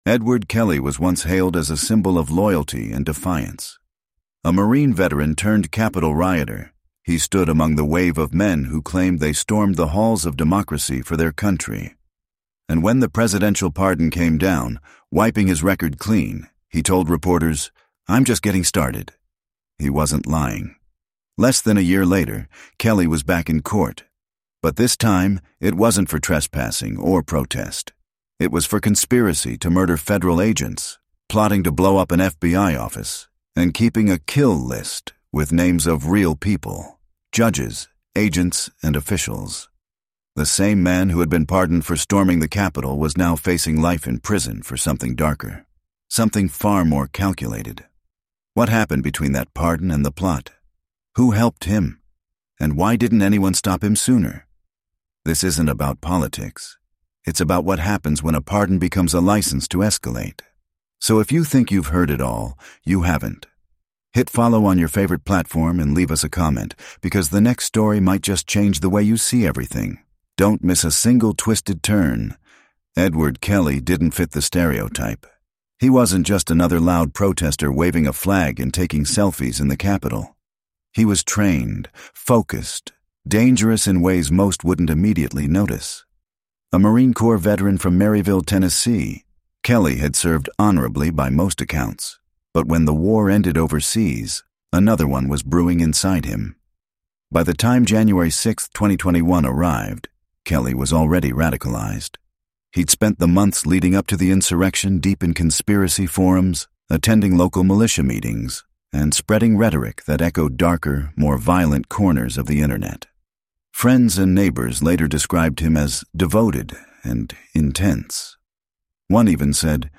But instead of redemption, he orchestrated a chilling conspiracy: bombs, kill lists, and an ambush timed for Independence Day. This 7-chapter true crime documentary unveils a disturbing crime investigation laced with political scandal, forensic evidence, and the dark history of one man’s descent into extremism. From secret recordings to courtroom drama, you'll hear the real voices, real plans, and real terror that turned a pardon into a powder keg.